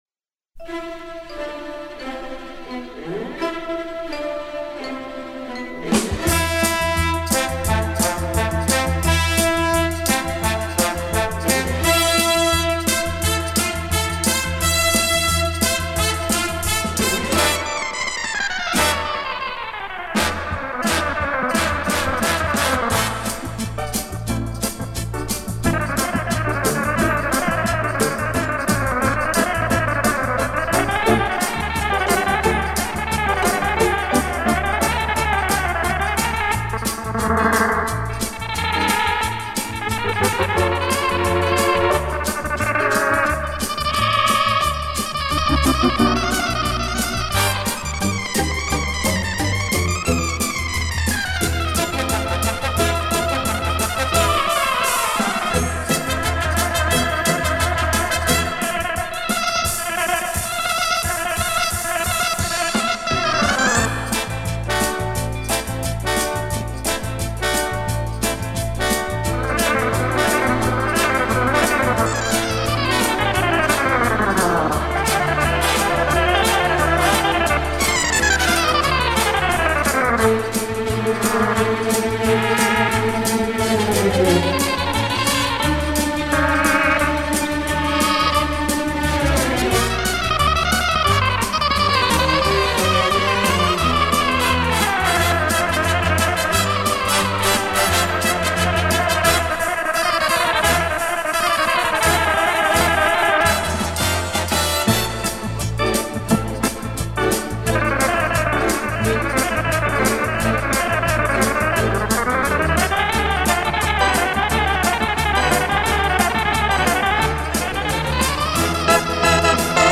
大师的高超演奏技巧, 更能强烈地将大黄蜂的飞行意境 像真地用音乐形象化.